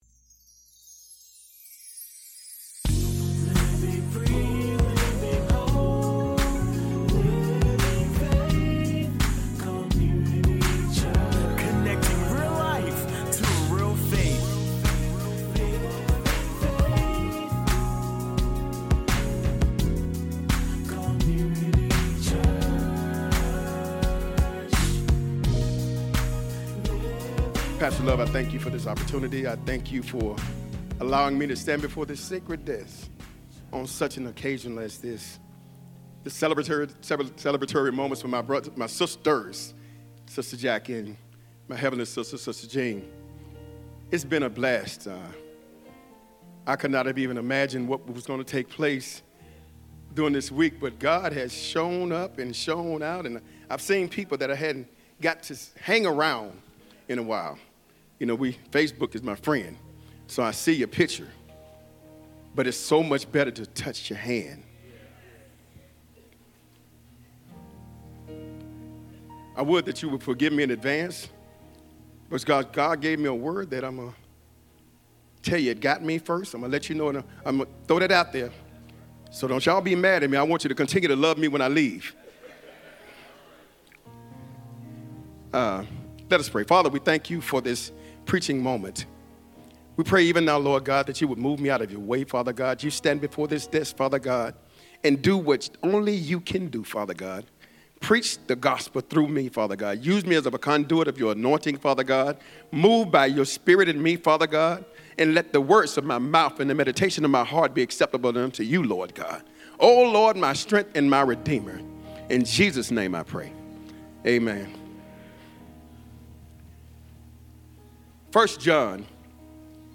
Sermons | Living Faith Community Church